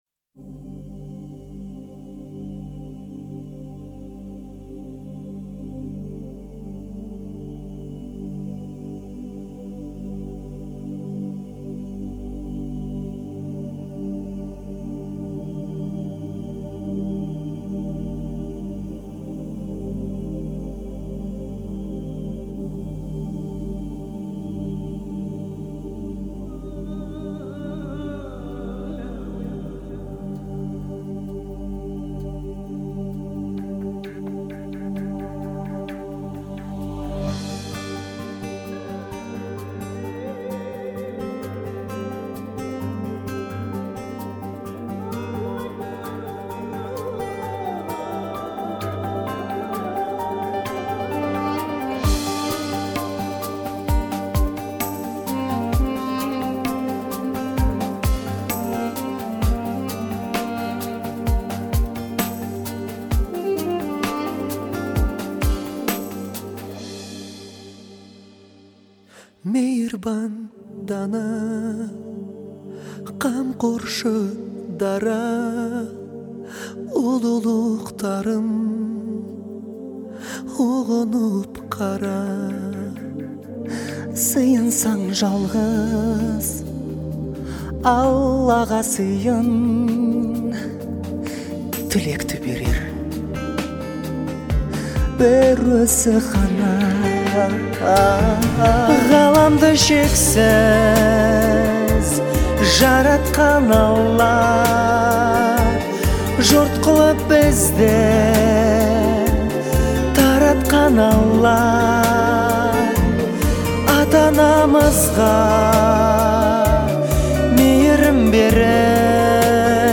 выполненная в жанре поп с элементами этники.